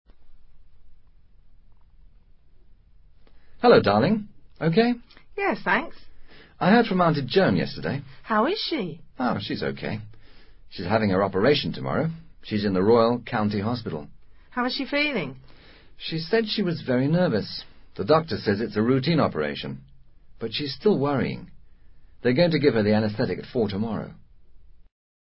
Breve diálogo en el que dos personajes hablan sobre una operación.